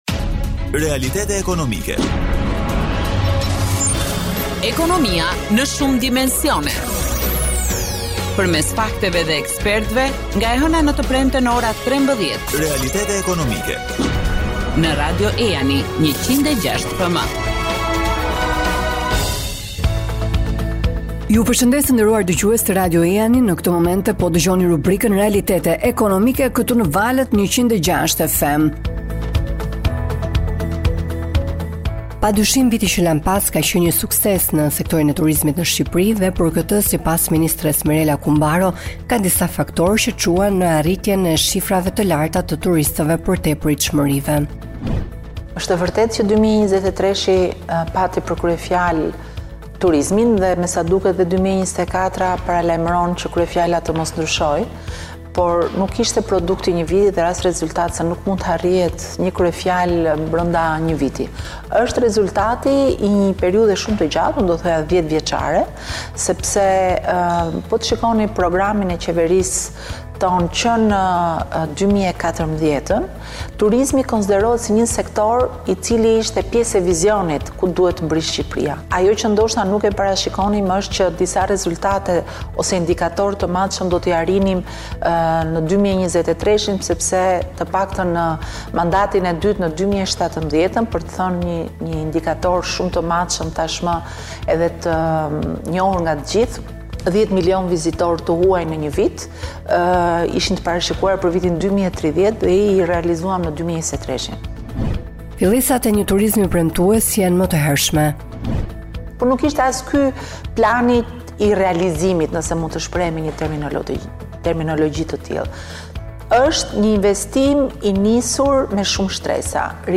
Ministrja Kumbaro tregon në një intervistë për mediat çelësin e suksesit në këtë drejtim.